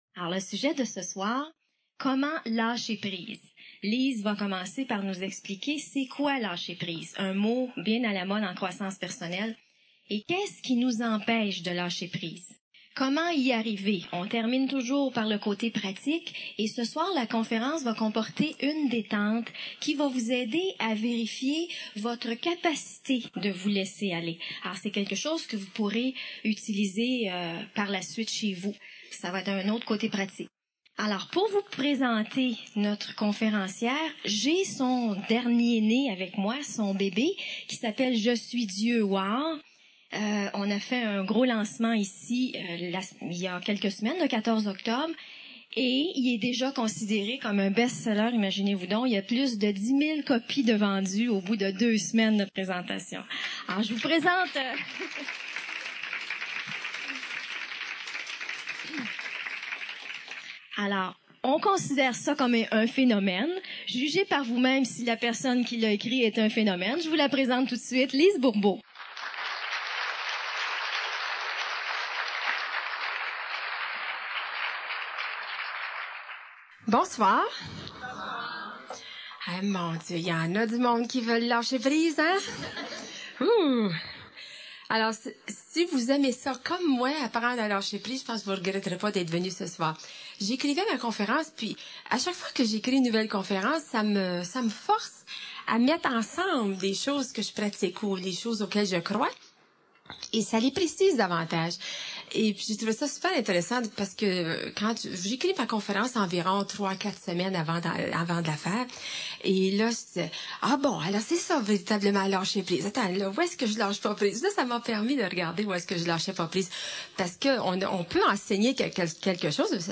1) Conférence de Lise Bourbeau «Comment lâcher prise»
Une partie de questions-réponses termine cette conférence pour nous aider à nous diriger plus facilement vers le lâcher prise.